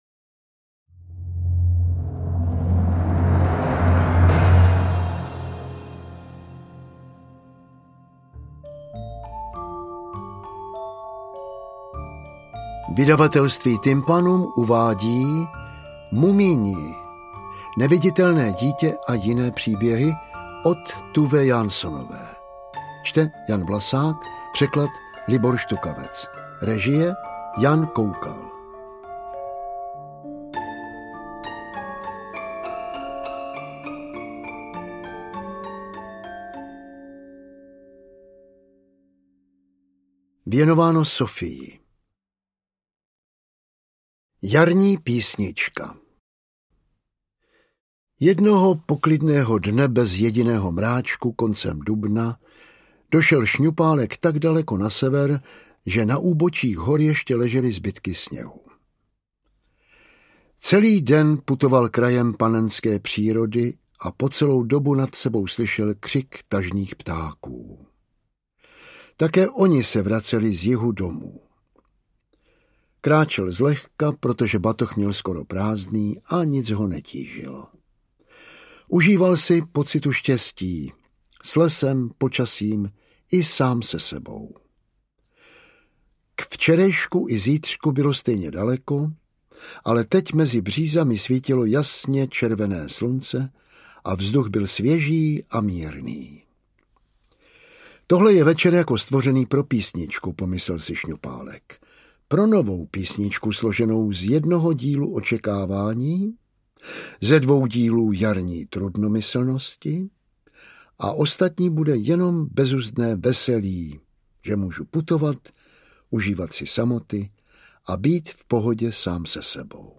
Interpret:  Jan Vlasák
AudioKniha ke stažení, 10 x mp3, délka 3 hod. 42 min., velikost 204,1 MB, česky